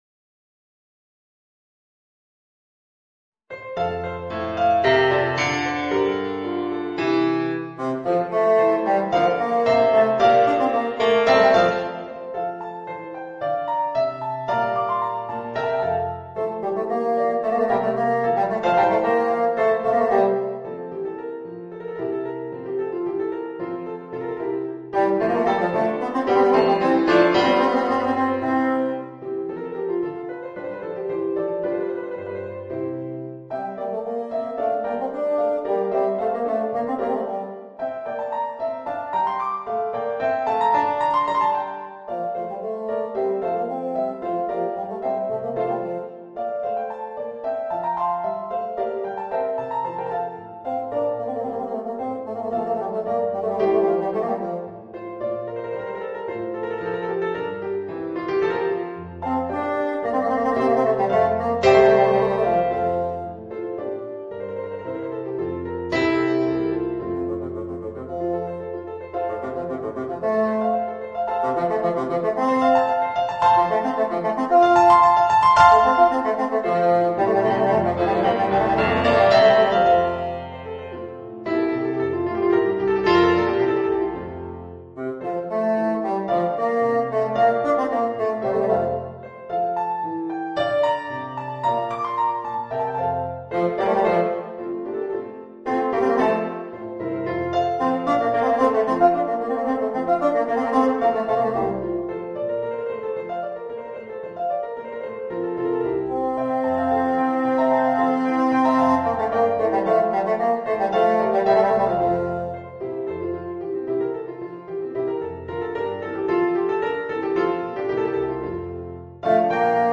Voicing: Bassoon and Organ